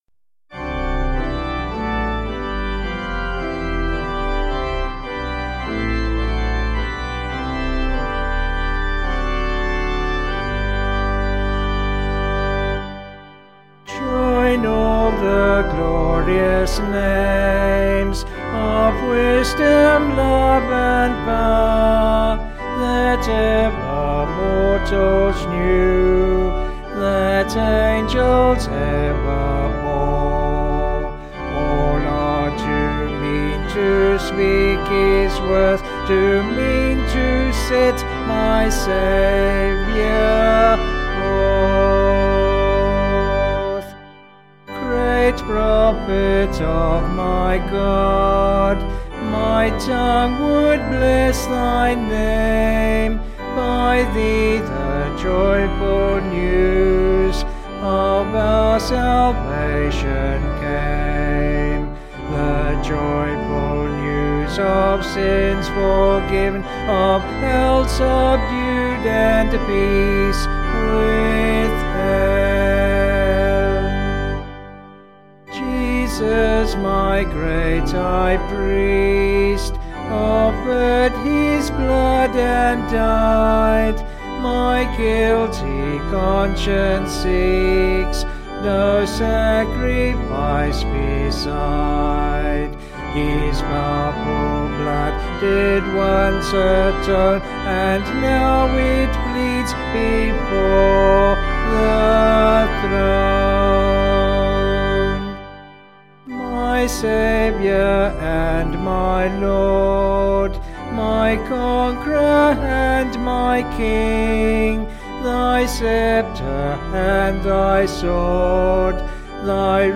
Vocals and Band   264.9kb Sung Lyrics 3.3mb